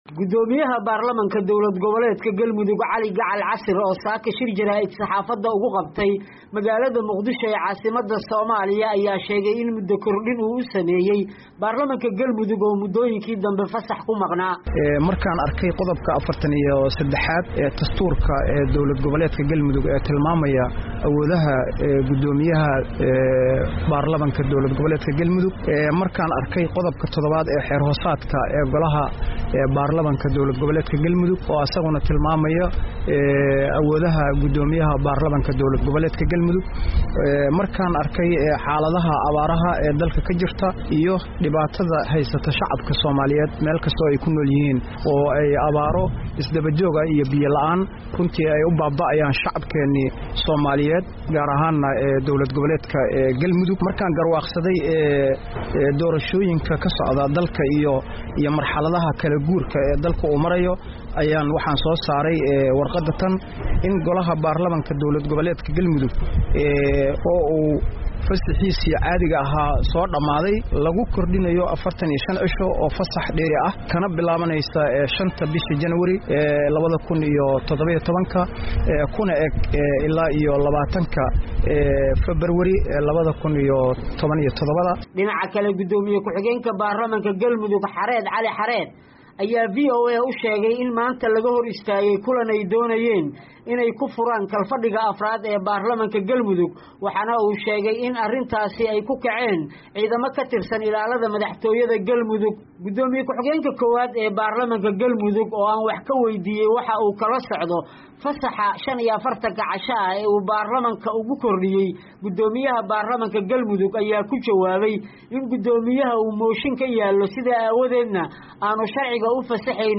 Dhagay warbin ay VOA ka diyaarisay